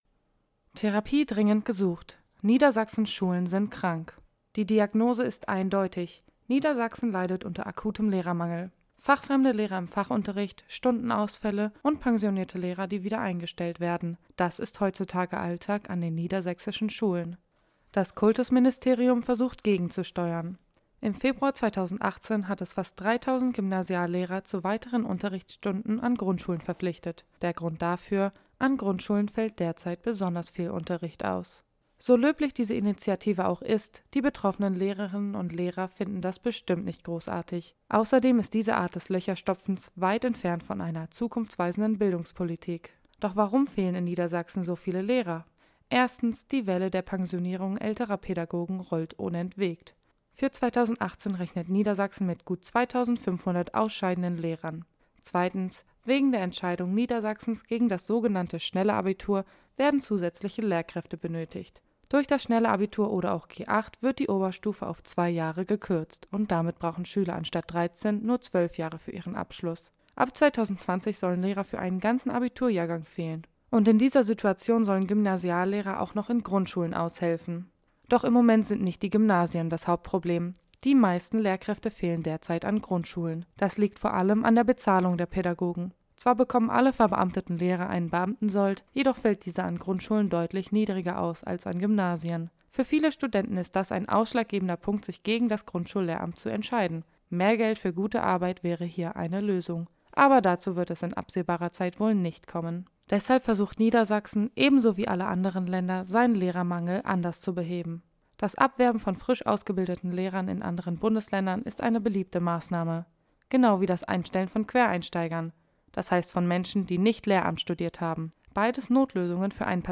Audiokommentar